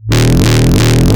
Desecrated bass hit 20.wav